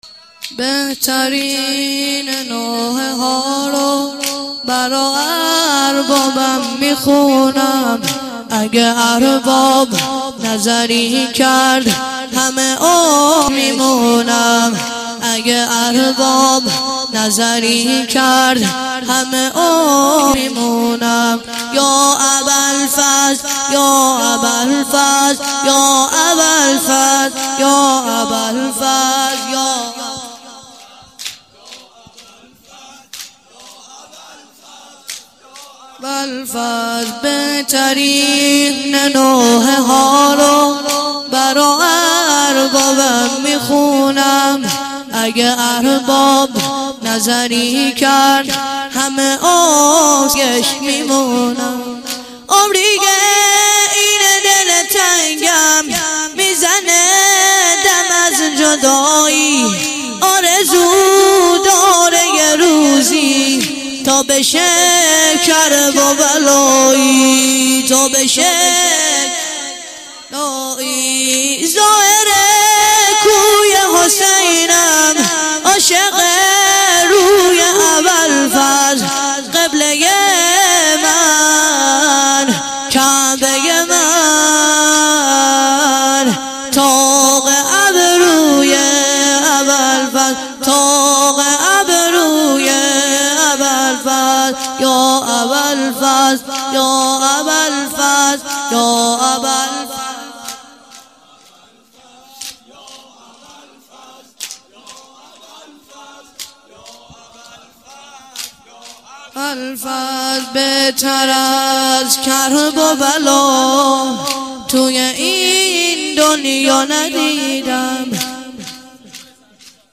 چهارضرب - بهترین نوحه ها رو برا اربابم میخونم